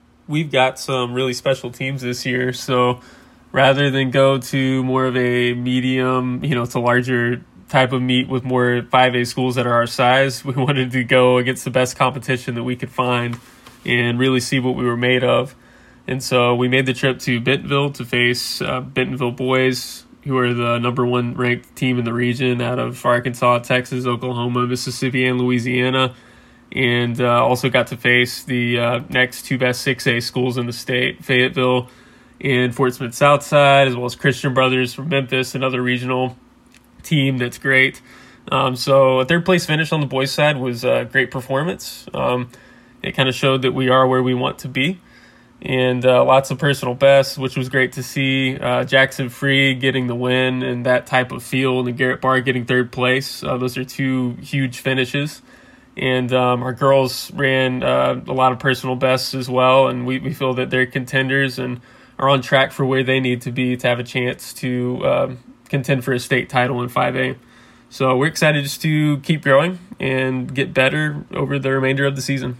shares his thoughts after the races.